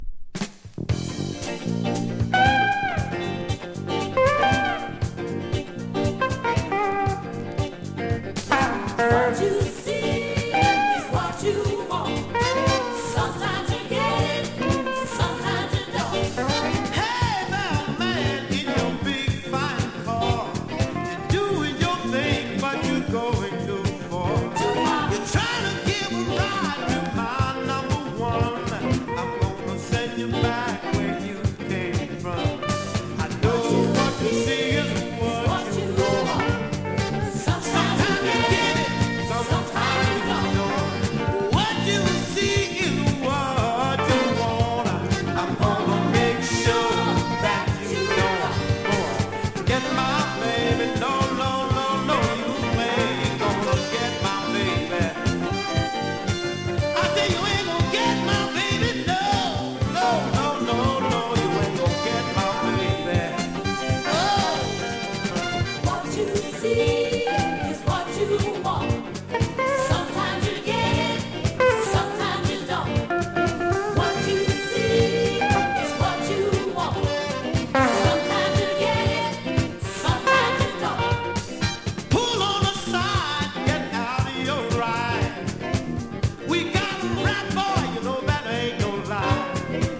しっとりと深みのあるテンダーなサザン・スロー・バラード